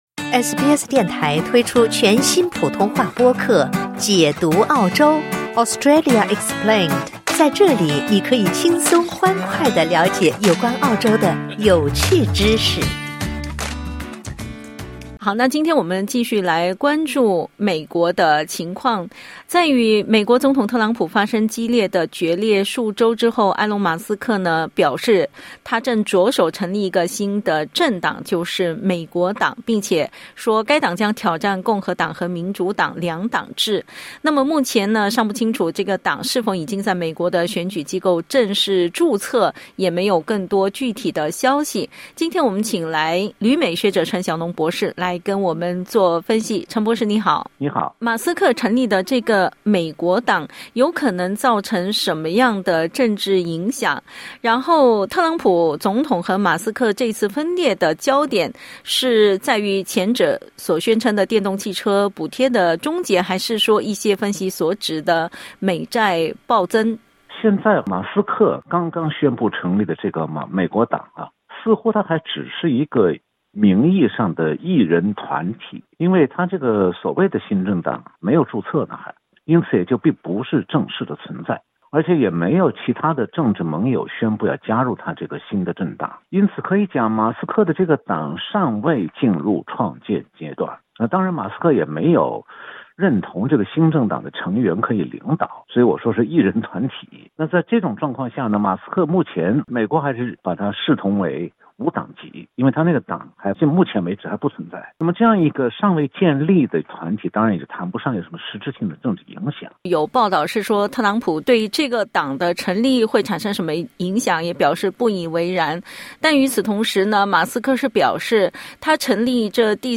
采访内容仅为嘉宾观点 欢迎下载应用程序SBS Audio，订阅Mandarin。